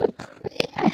endermen